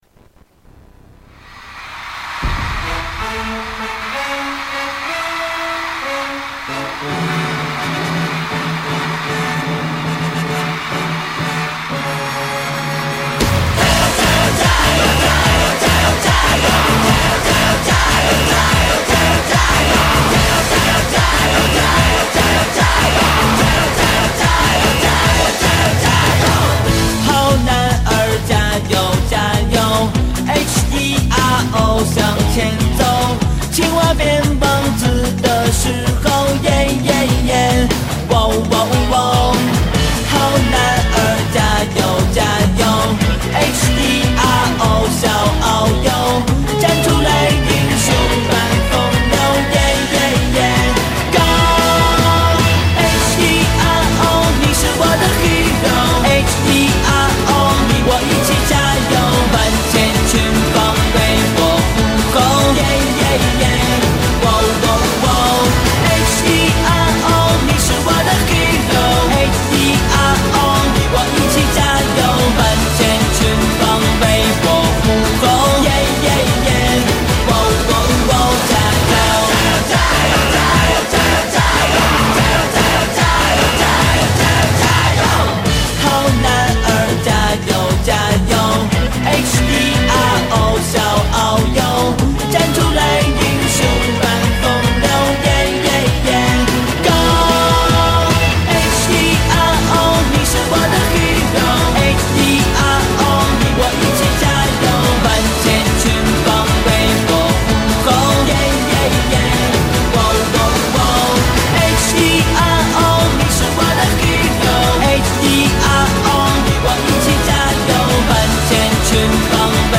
背景音乐擂鼓助威，坐看精彩！